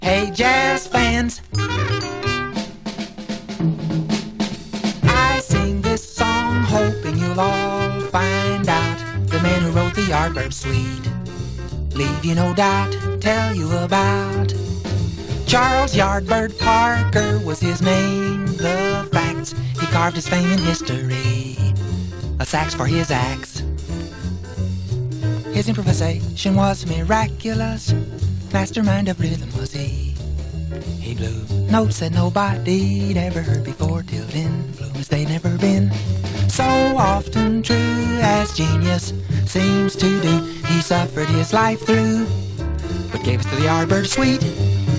Jazz, Pop, Vocal　USA　12inchレコード　33rpm　Stereo